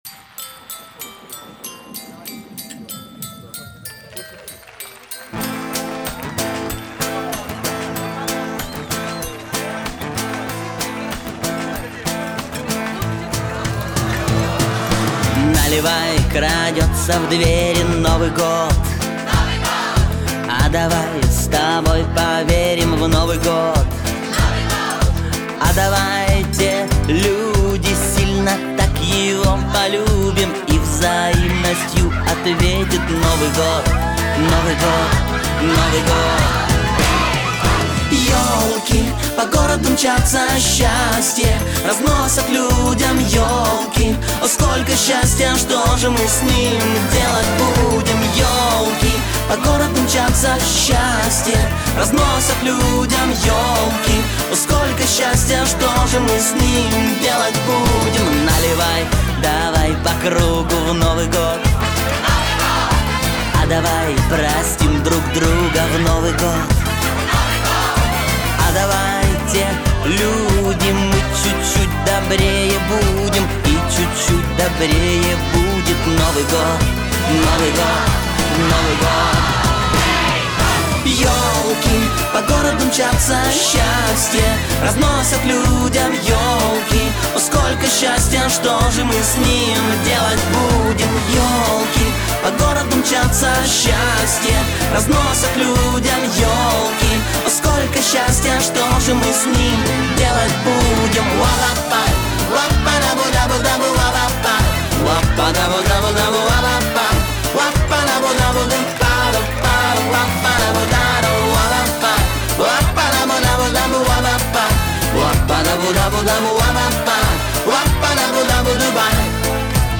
яркие мелодии с зажигательными ритмами